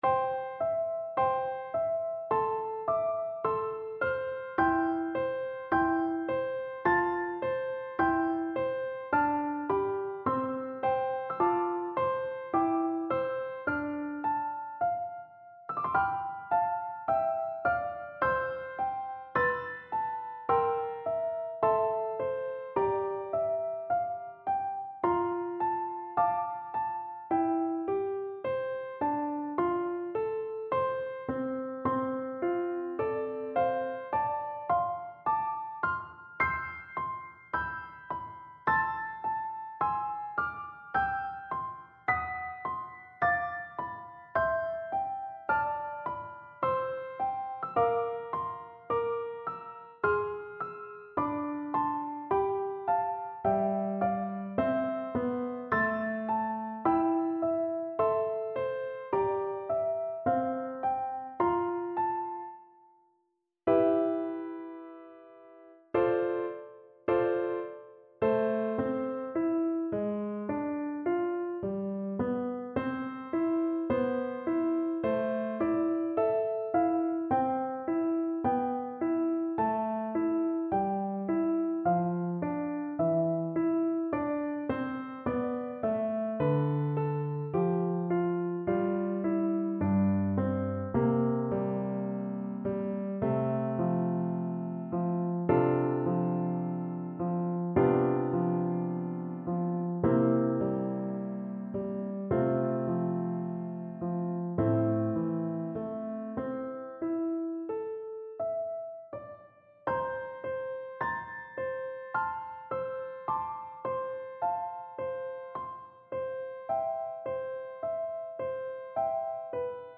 Flute
Traditional Music of unknown author.
4/4 (View more 4/4 Music)
C major (Sounding Pitch) (View more C major Music for Flute )
Andante